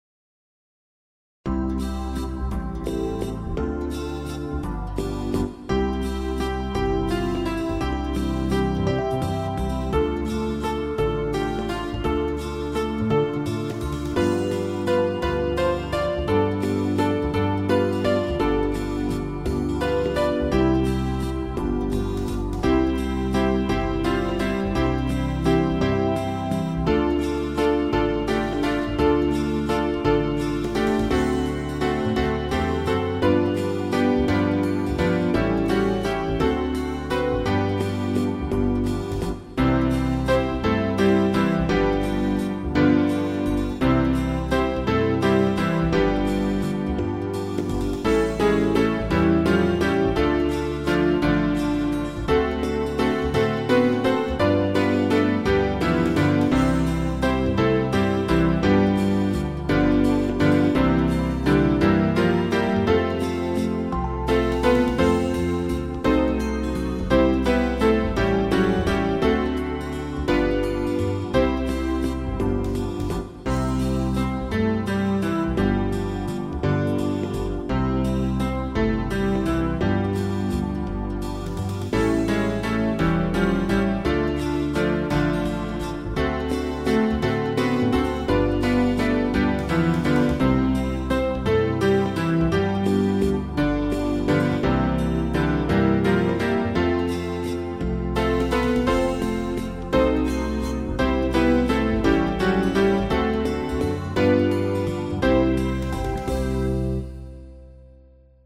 12-beat intro.
This song is in 3/4 waltz time.